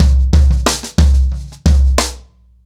Wireless-90BPM.41.wav